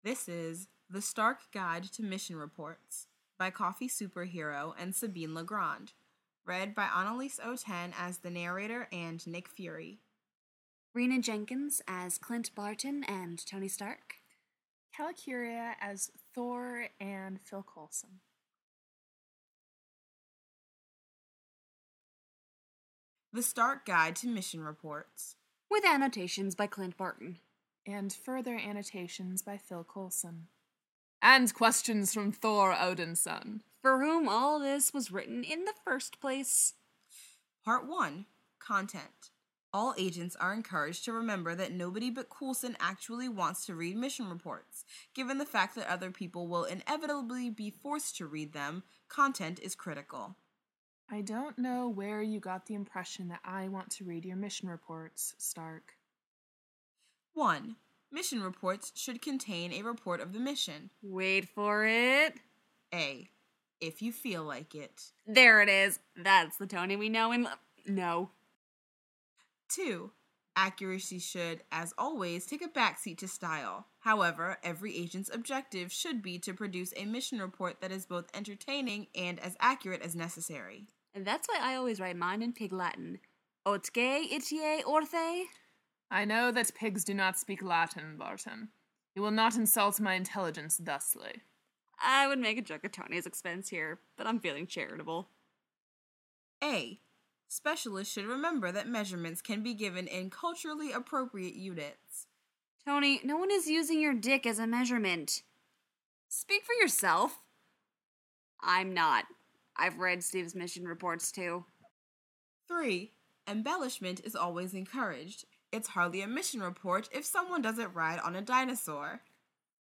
info|gen, collaboration|ensemble